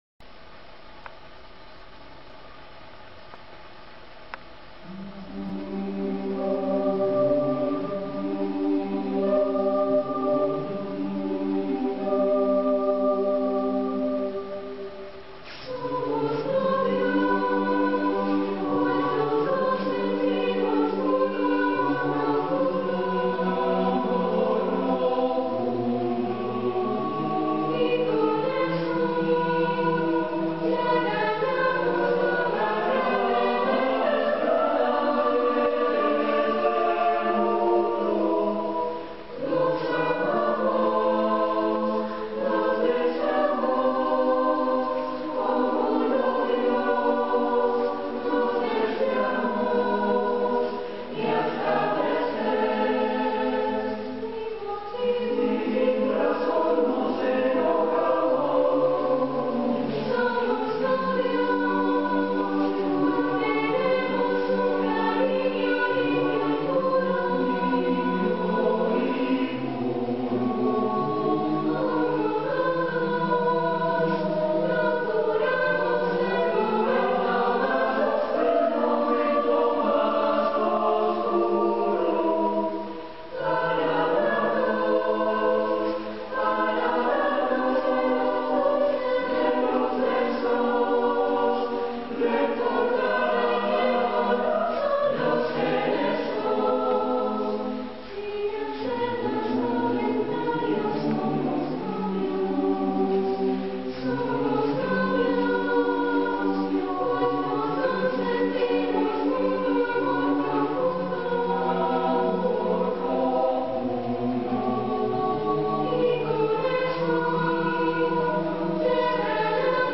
Formación:SATB
Género:Bolero